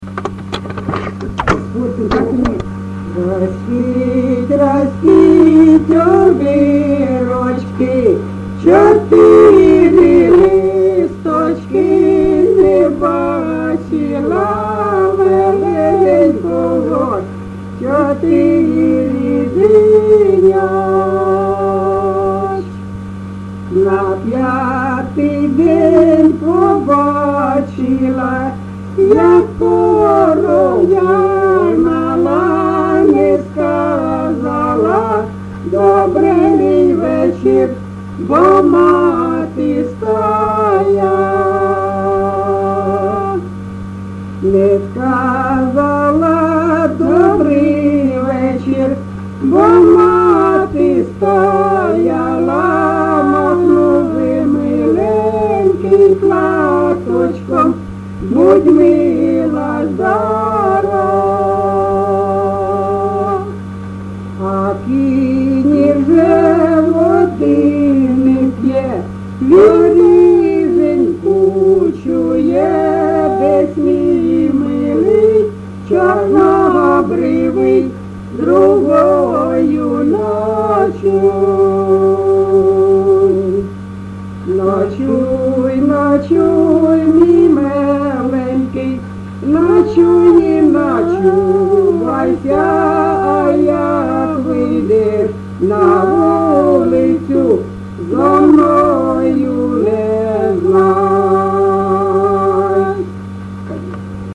ЖанрПісні з особистого та родинного життя
Місце записус. Калинове Костянтинівський (Краматорський) район, Донецька обл., Україна, Слобожанщина